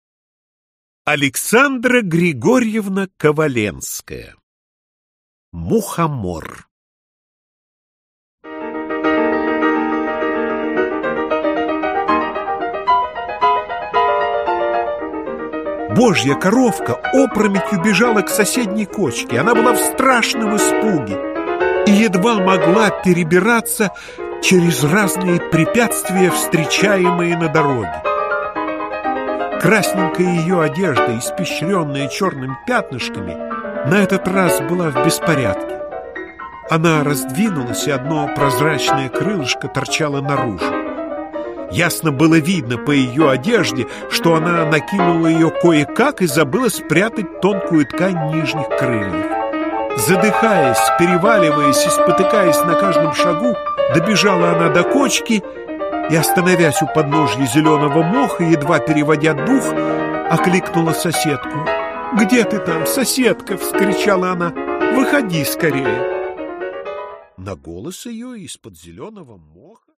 Аудиокнига Добрым детям сказки | Библиотека аудиокниг